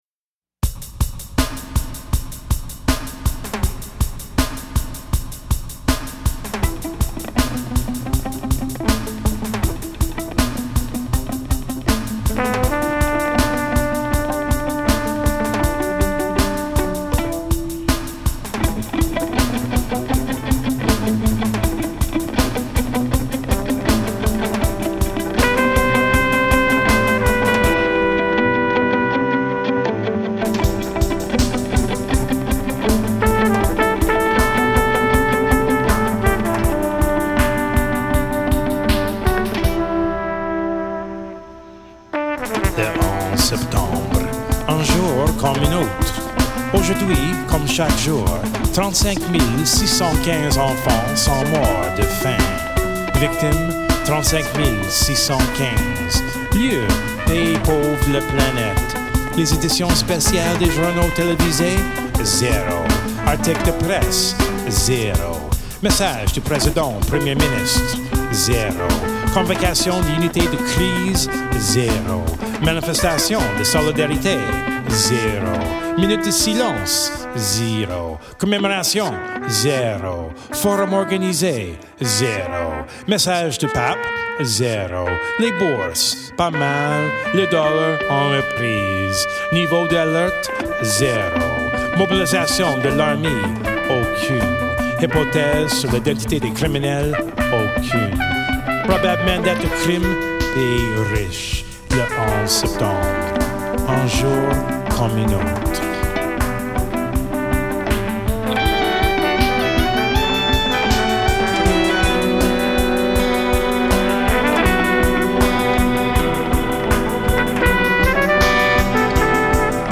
guitar/special effects
violin
trumpet.